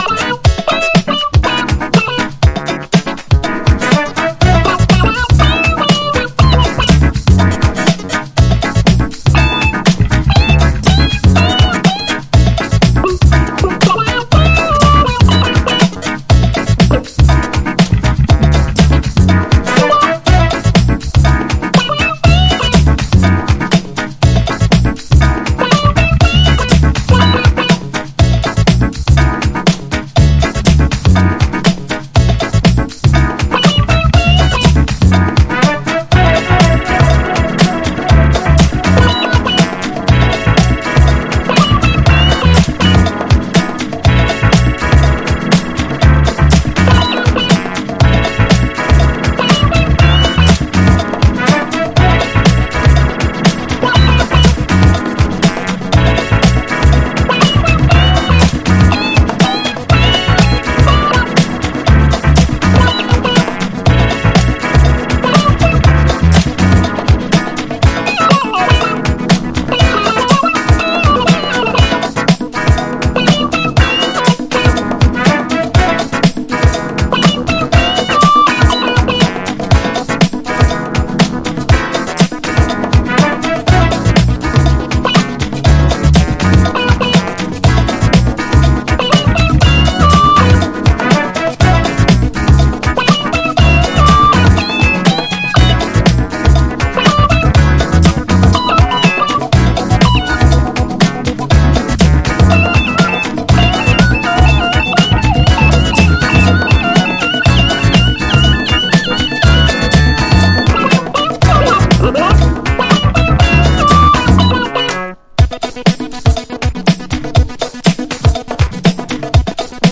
Alt Disco / Boogie
切れ味鋭いビートにファニーなサンプリングやメロディーメイク
生を意識したブギービートシーケンス。